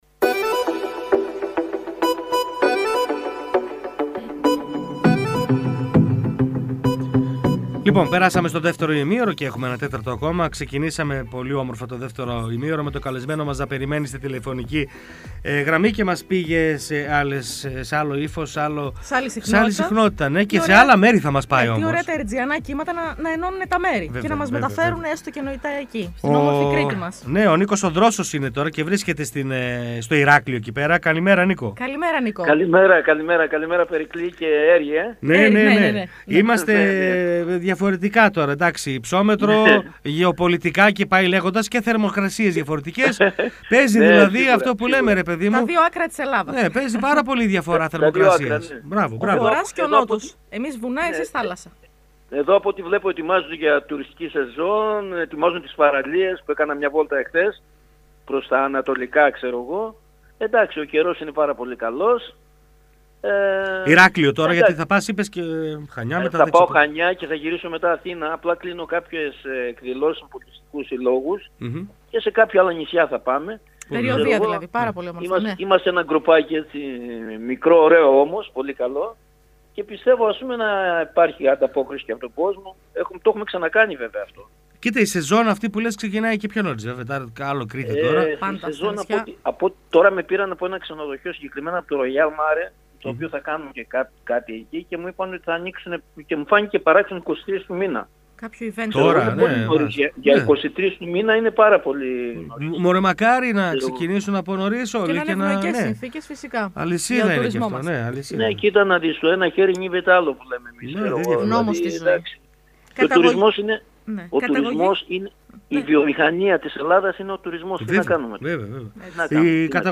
«Μέρα μεσημέρι» Καθημερινό ραδιοφωνικό μαγκαζίνο που ασχολείται με ρεπορτάζ της καθημερινότητας, παρουσιάσεις νέων δισκογραφικών δουλειών, συνεντεύξεις καλλιτεχνών και ανάδειξη νέων ανθρώπων της τέχνης και του πολιτισμού.